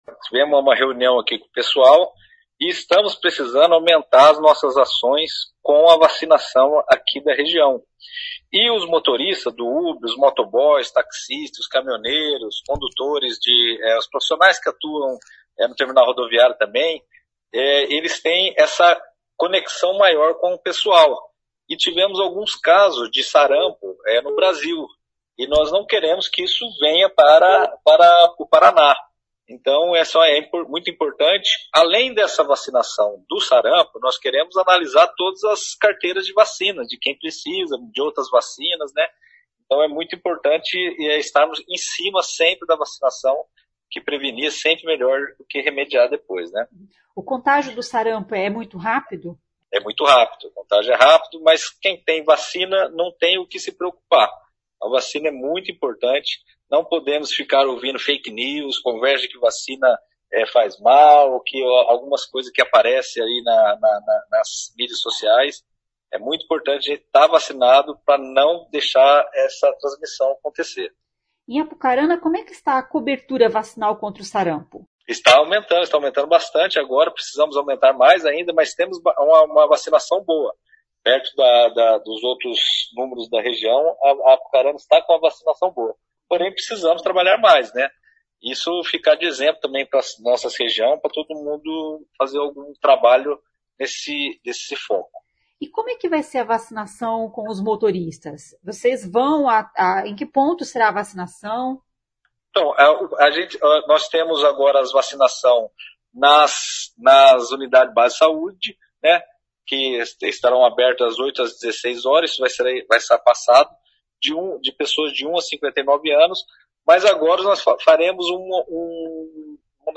Ouça o que diz o secretário: